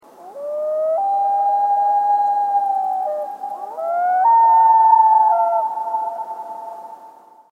Common loons
One of my favorite things about northern Wisconsin (where I just returned from camping) are the common loons that spend the summers there.
The haunting call the loon makes at night is probably the biggest reason I like them so much. There is nothing quite like laying in your sleeping bag and hearing the loons wail as you’re drifting off to sleep.
loon-wail.mp3